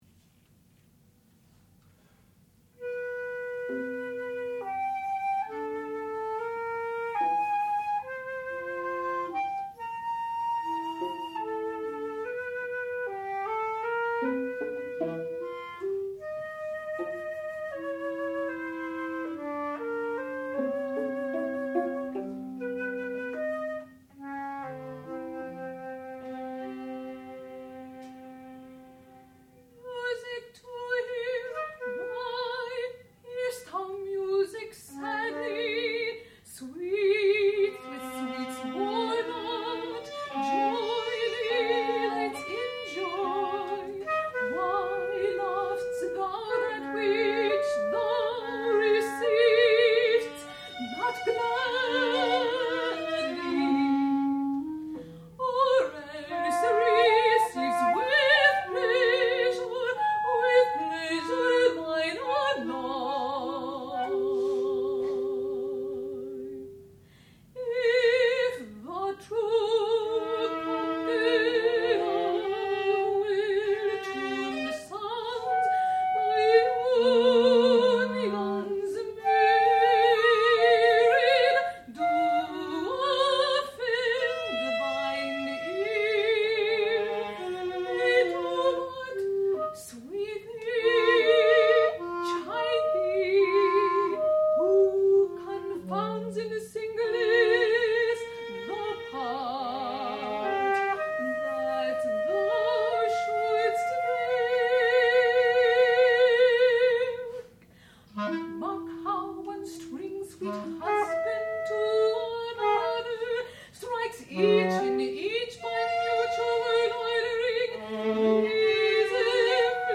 sound recording-musical
classical music
clarinet
flute
viola